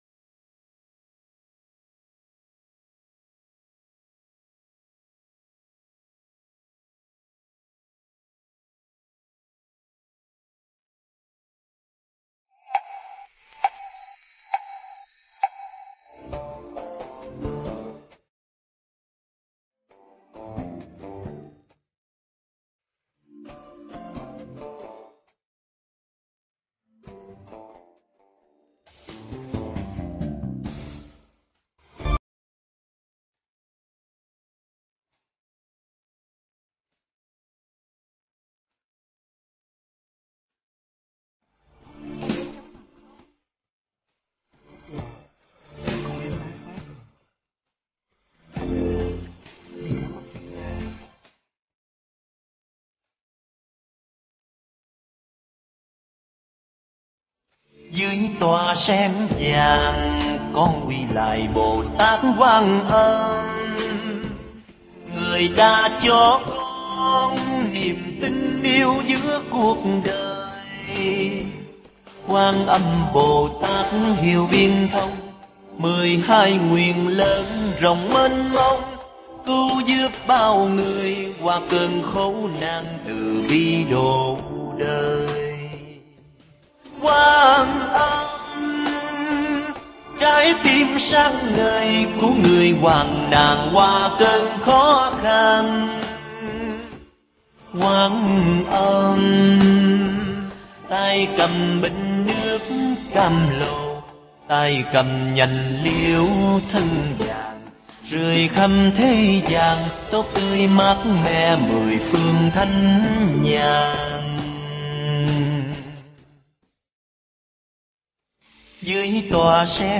Pháp âm Đoàn từ thiện duyên lành – Làm từ thiện tại tỉnh Lâm Đồng - Thầy Thích Nhật Từ